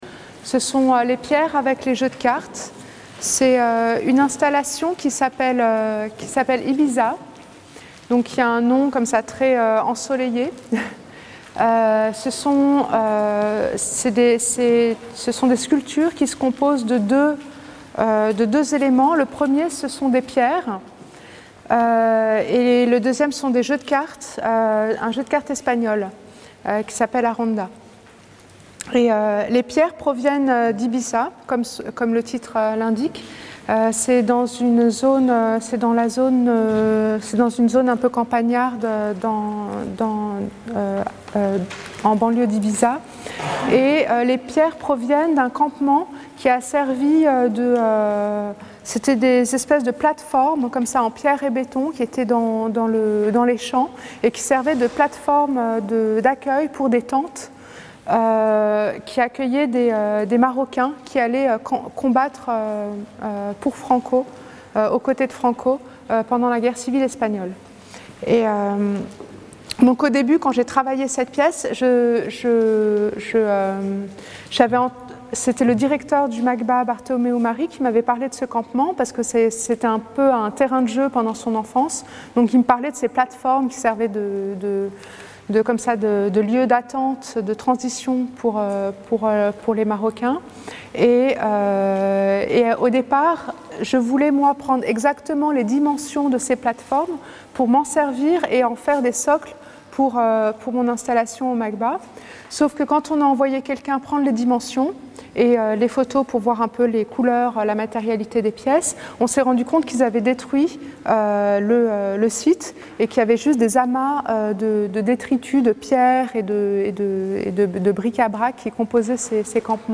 Le macLYON a proposé à Latifa Echakhch de parler de chacune des œuvres présentes dans l'exposition Laps, et a intégré ces séquences sonores à ses cartels.